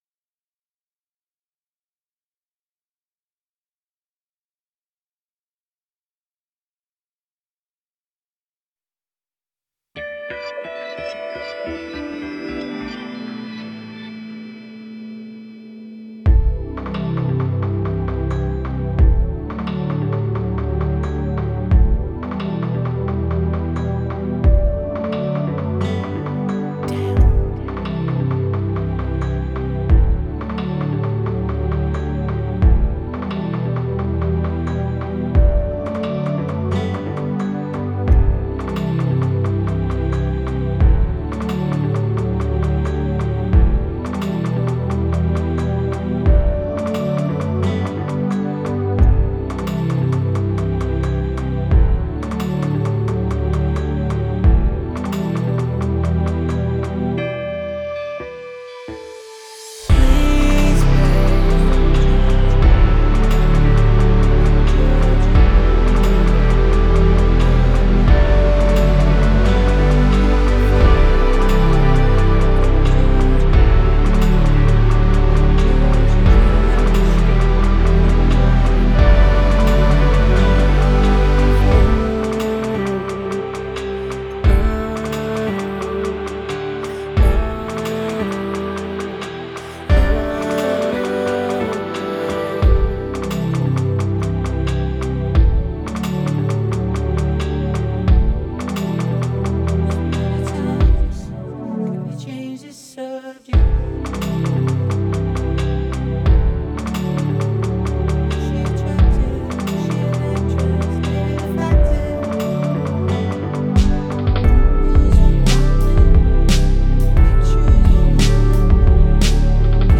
Hip Hop , R&B , Rap